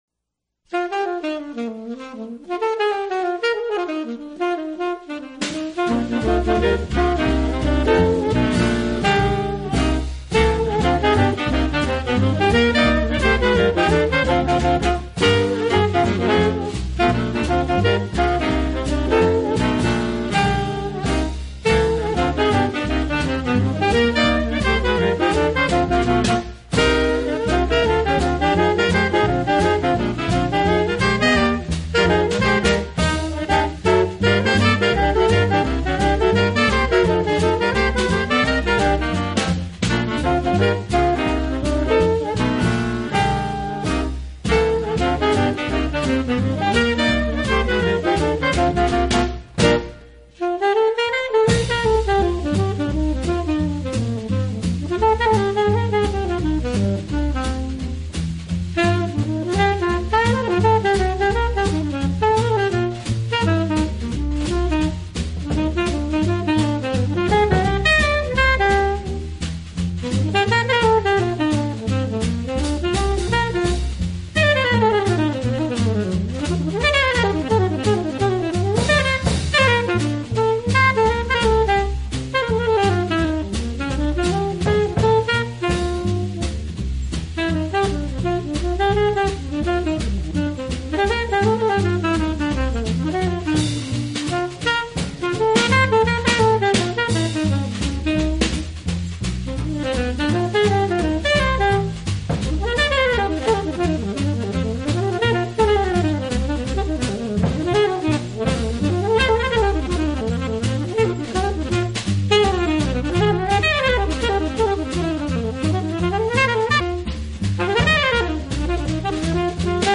音乐类型：Jazz
次中音萨克斯
上低音萨克斯